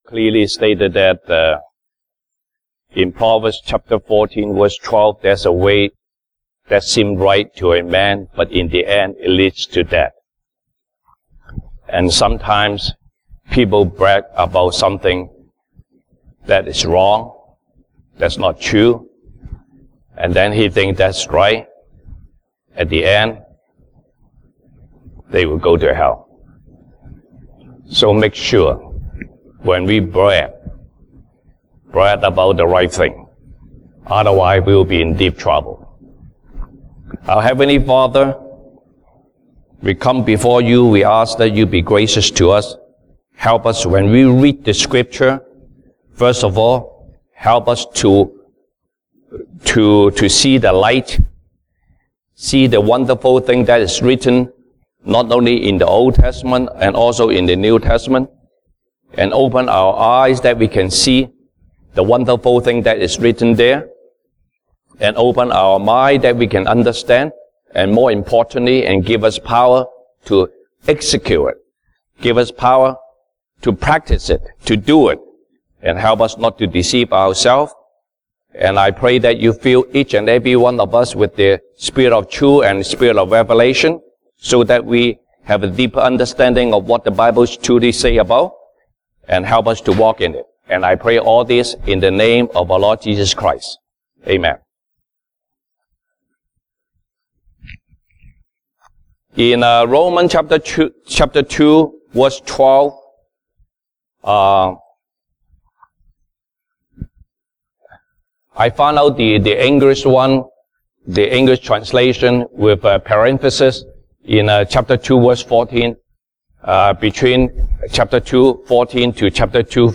Sunday Service English Topics